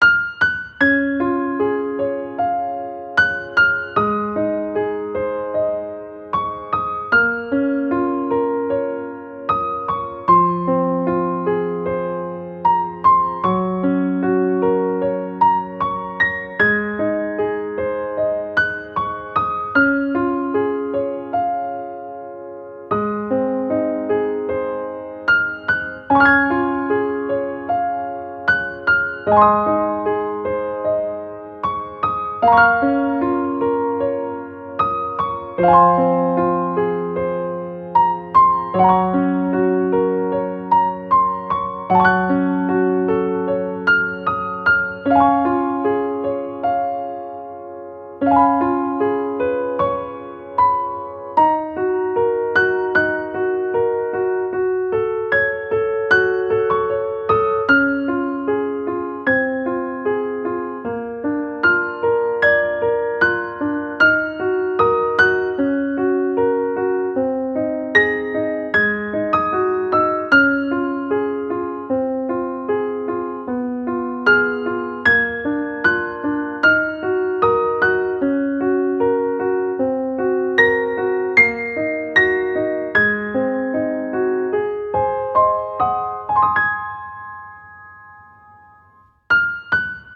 ogg(L) 楽譜 癒し しっとり メロディアス
丁寧に歌うように。